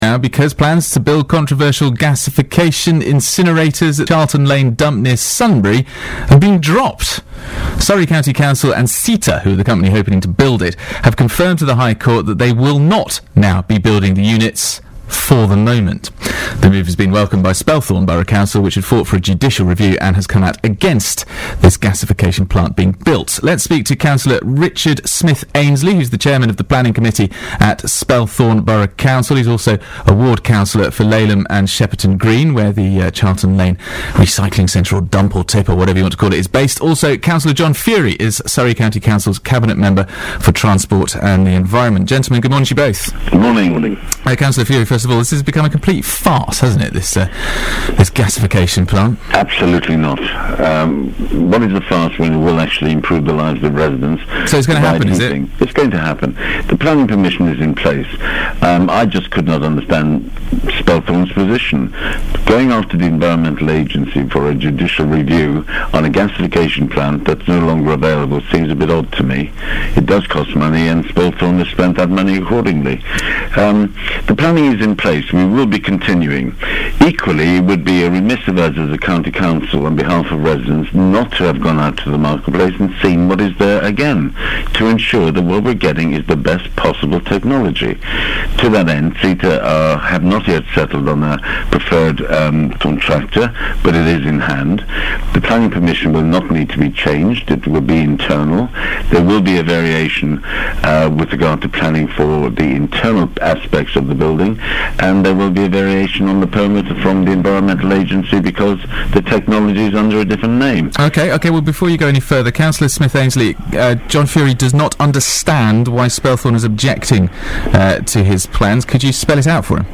BBC Surrey interview about Eco Park